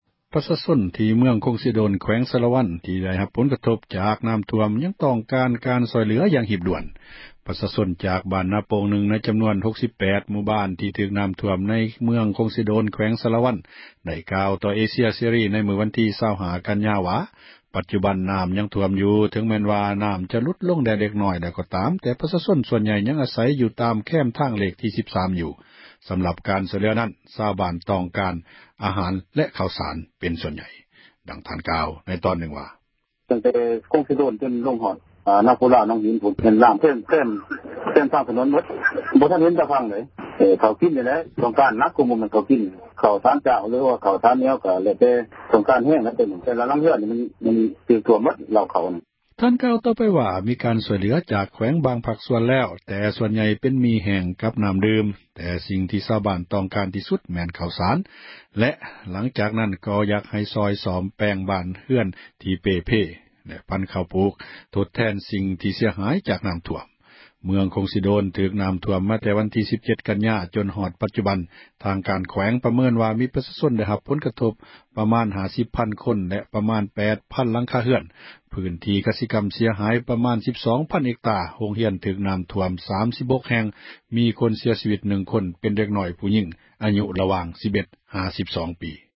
ປະຊາຊົນ ຈາກບ້ານນາປົ່ງນື່ງ ໃນຈຳນວນ 68 ບ້ານທີ່ຖືກນໍ້າຖ້ວມ ໃນເຂດ ເມືອງຄົງເຊໂດນ ແຂວງສາຣະວັນ ໄດ້ກ່າວຕໍ່ເອເຊັຽເສຣີ ໃນມື້ ວັນທີ 25 ກັນຍາ ວ່າ ປັດຈຸບັນນໍ້າຍັງຖ້ວມຢູ່ ເຖິງແມ່ນວ່ານໍ້າຈະຫລຸດລົງແດ່ແລ້ວກໍຕາມ ແຕ່ປະຊາ ຊົນສ່ວນໃຫຍ່ ຍັງອາສັຍ ຢູ່ແຄມທາງ ເລກທີ່ 13 ຢູ່ ສຳລັບການຊ່ອຍເຫລືອນັ້ນ ຊາວບ້ານຕ້ອງການອາຫານ ແລະເຂົ້າສານ.